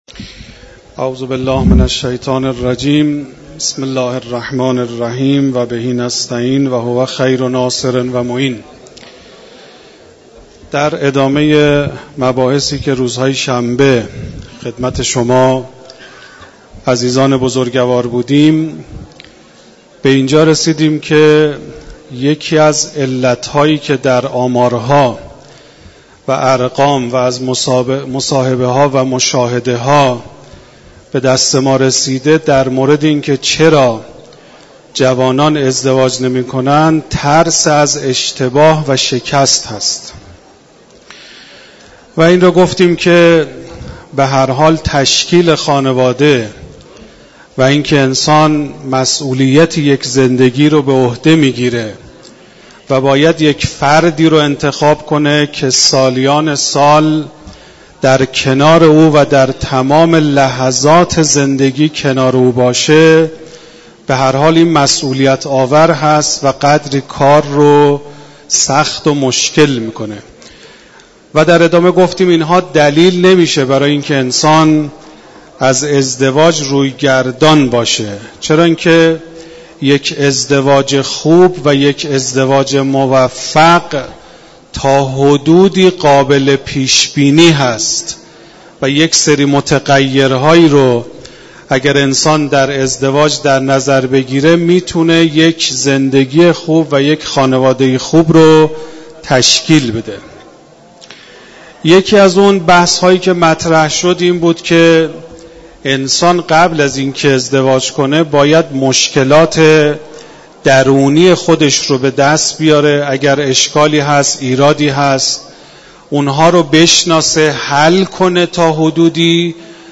بیان مباحث مربوط به خانواده و ازدواج در کلام مدرس حوزه و دانشگاه در مسجد دانشگاه کاشان - نهاد نمایندگی مقام معظم رهبری در دانشگاه کاشان